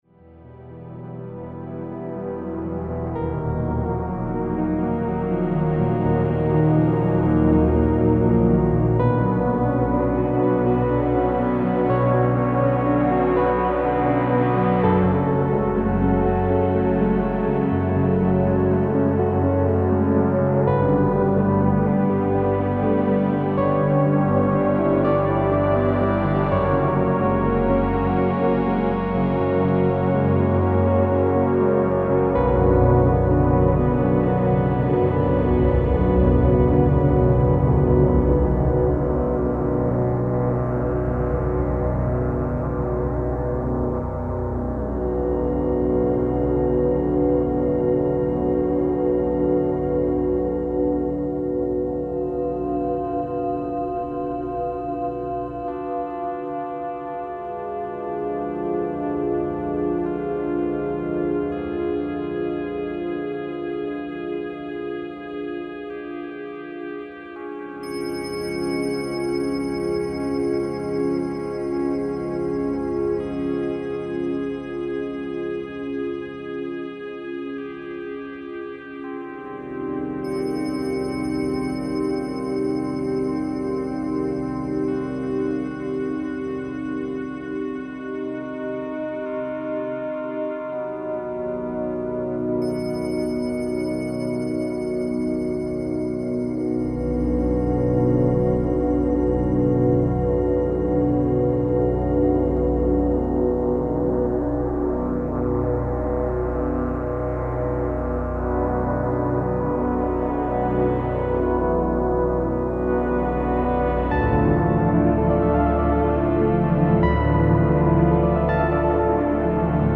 Musique électronique
poème symphonique